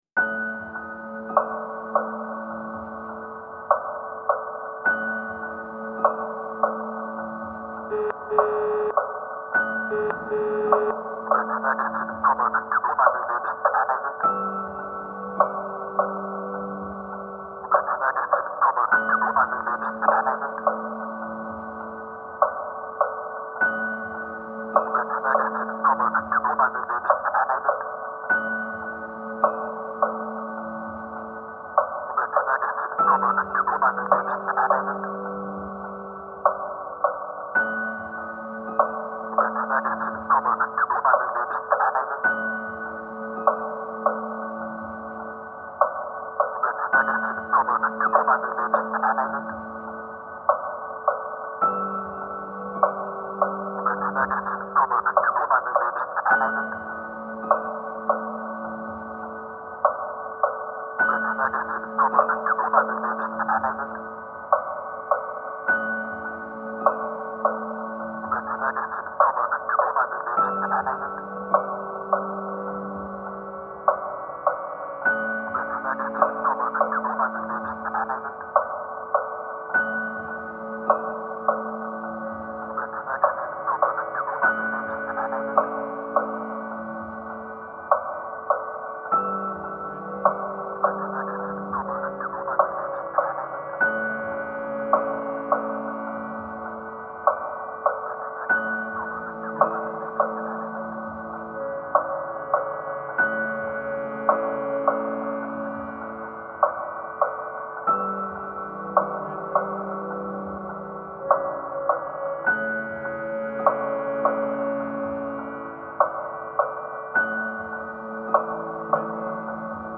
Dark ambient